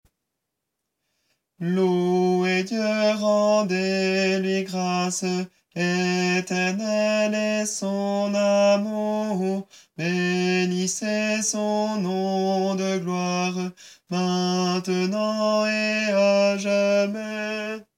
Voix chantée (MP3)COUPLET/REFRAIN
TENOR